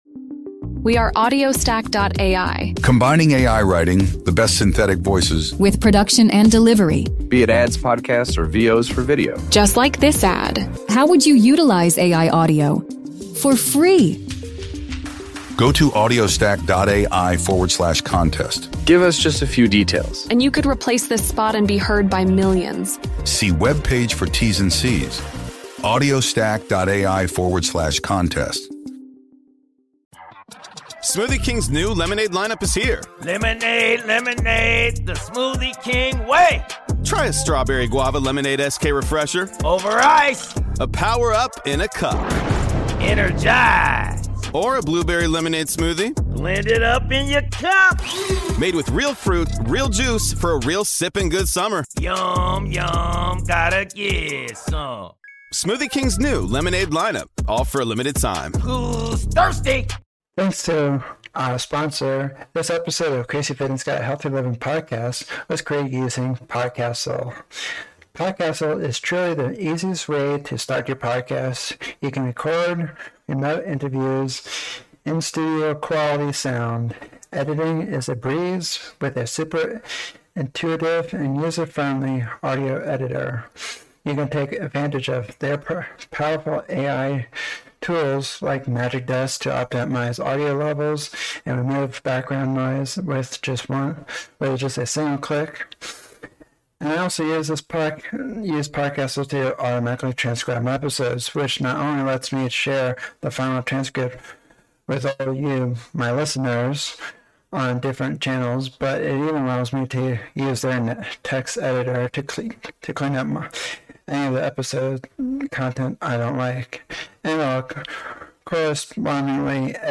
Tune in to the Wellness Wisdom trailer and discover essential mindfulness techniques and physical health tips designed to help you achieve true balance in mind and body. This insightful interview shares proven strategies you can use right away to boost your overall wellness, find inner calm, and feel more energized.